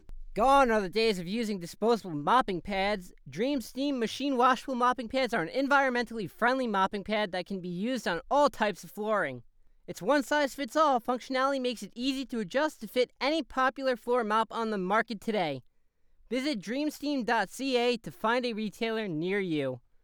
advertisement for dreamsteam mop cleaning supplies - sample
high tone. medium tone, new yorker,
Ranging from mid-high pitched tones, neardy, and nasally.
Microphone Rode NT1